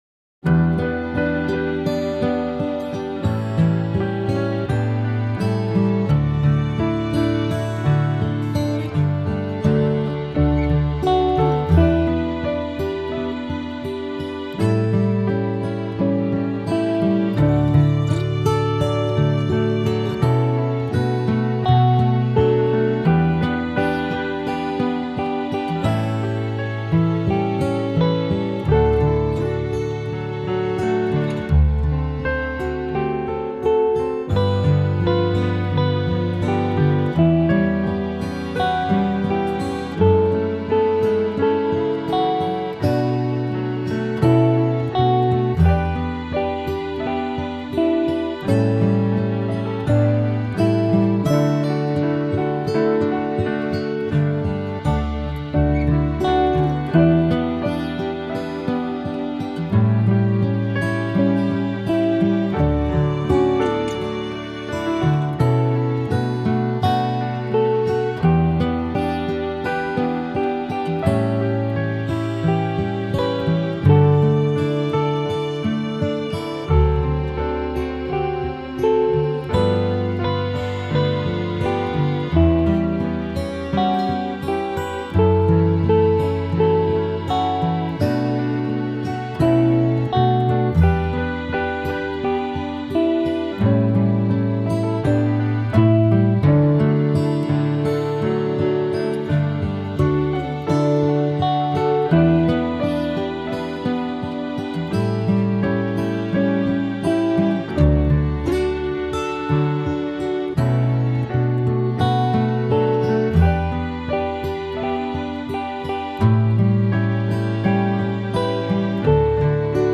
It is a gentle song
chant like and nicely simple for endless repetition